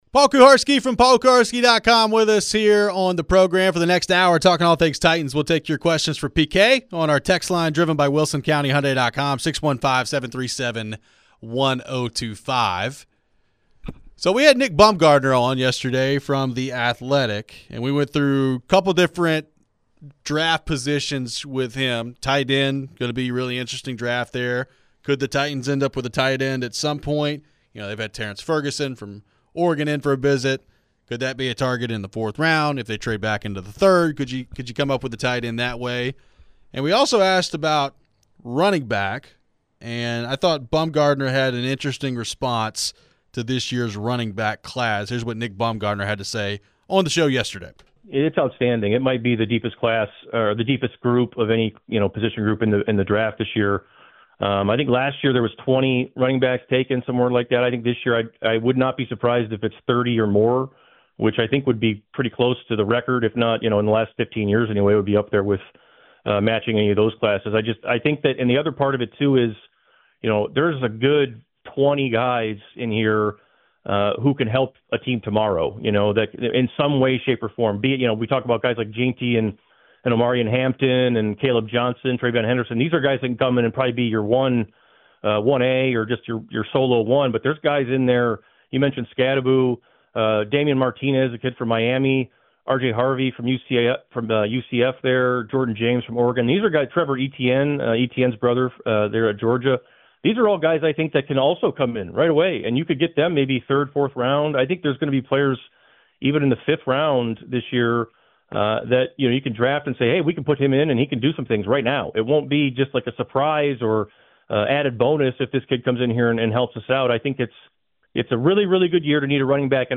What about the possibility of the Titans drafting a running back this year? We take your phones.